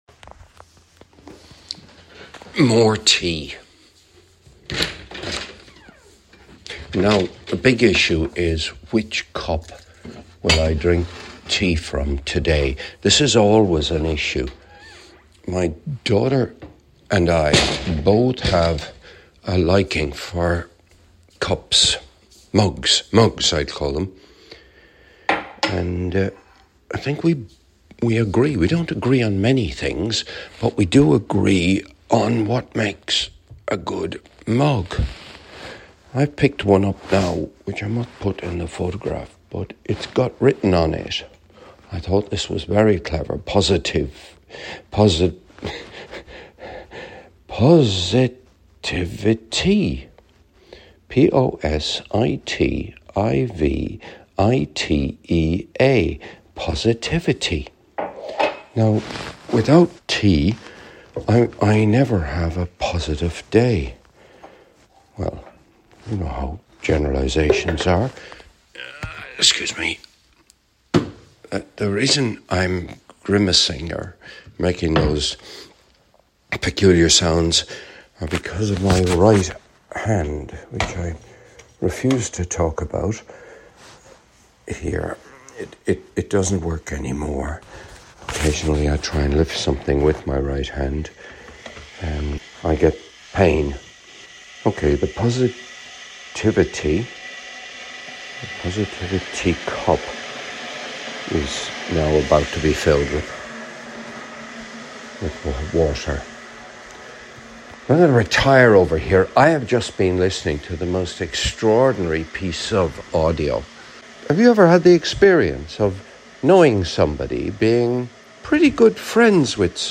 This episode was recorded in my kitchen on Friday 15th 2024.